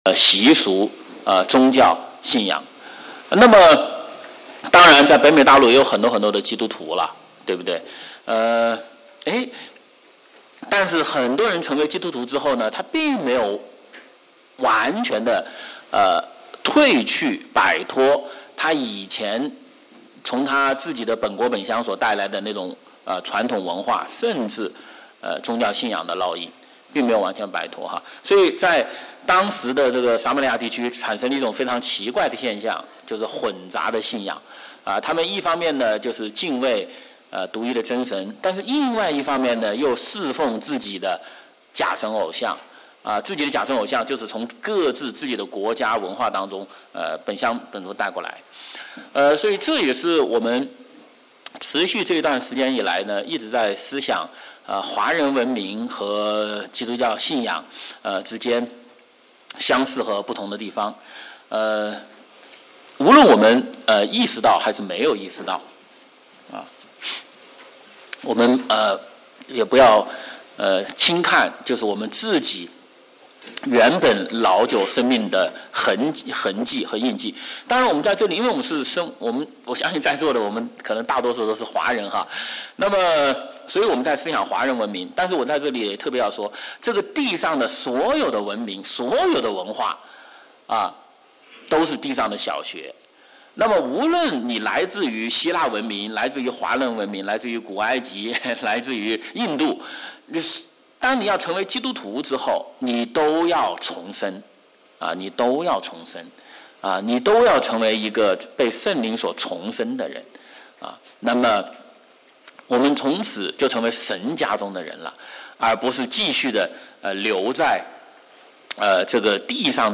主日学